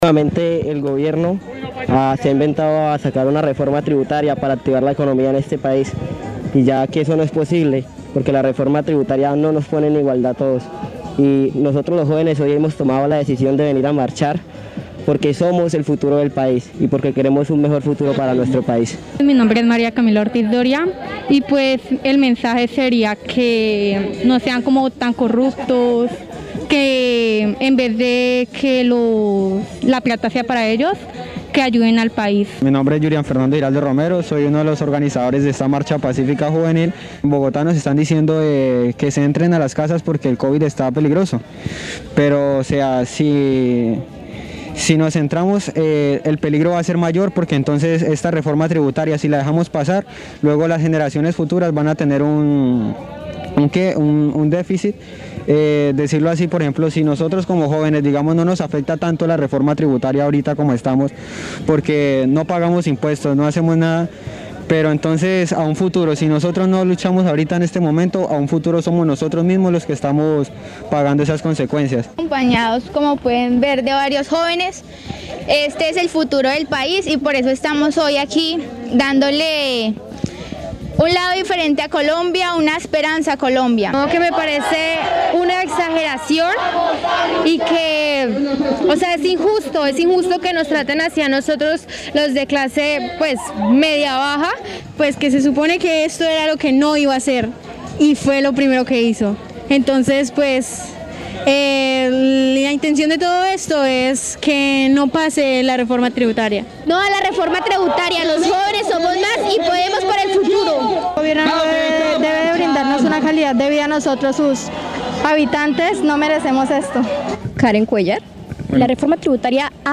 Voceros se pronunciaron a través Marandua Noticias sobre el rechazo a la propuesta del Gobierno nacional de la Reforma Tributaria.